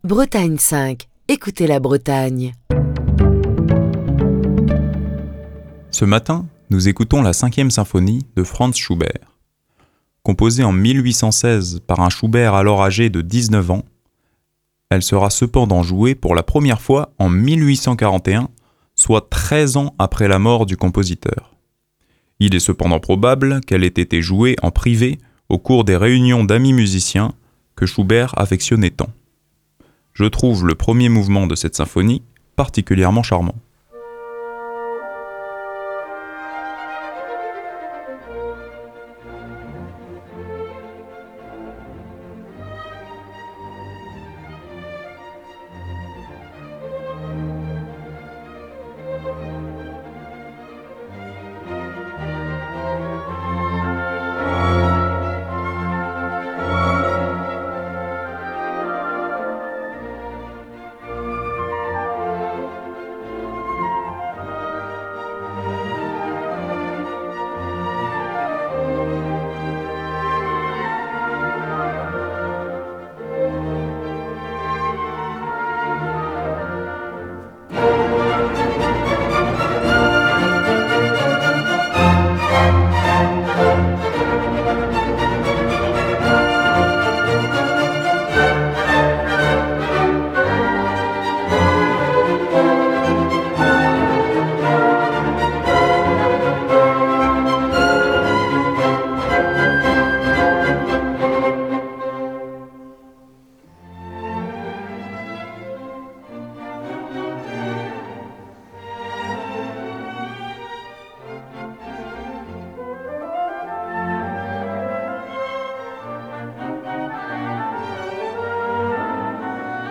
Franz Schubert composa la Symphonie n° 5 en si bémol majeur (D. 485) en septembre-octobre 1816, alors qu'il n'avait que de 19 ans.
interprété par l'Orchestre philharmonique de Berlin dirigé par Nikolaus Harnoncourt